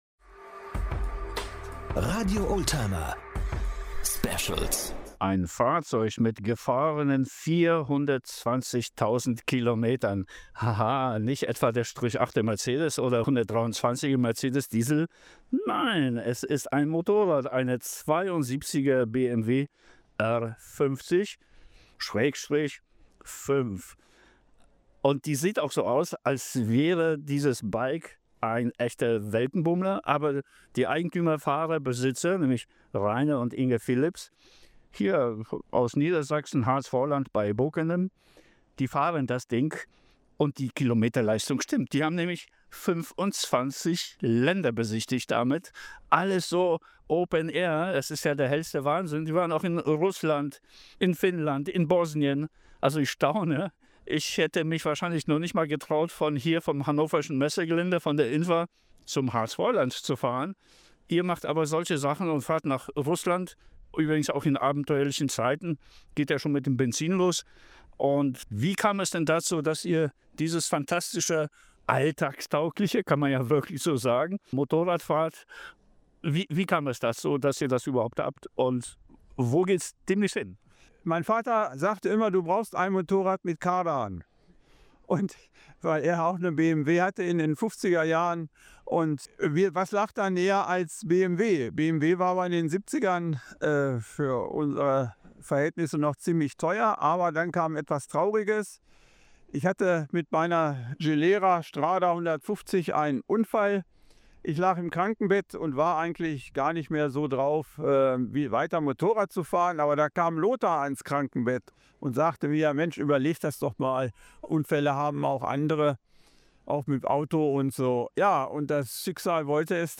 Interviews & Reportagen - Verpasste Sendungen | RADIO OLDTIMER